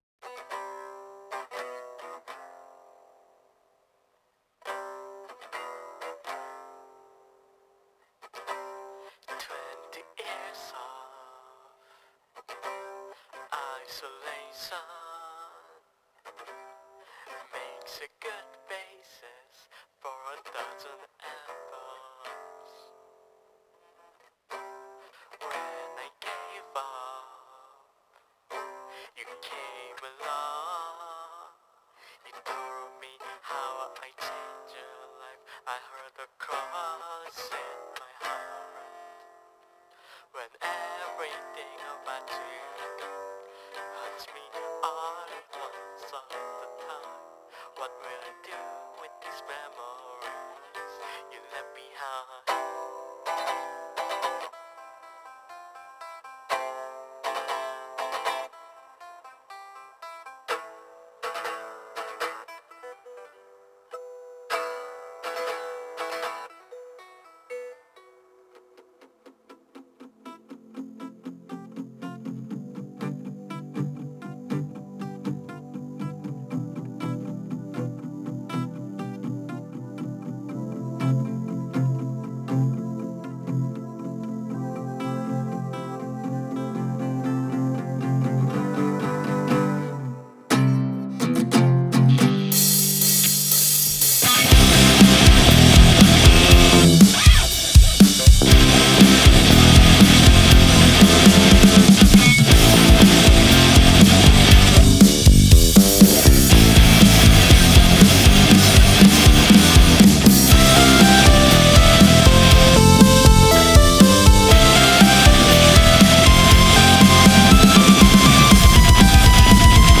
It's an emo album about being a sad bunny.
Guitar, bass, bg vocals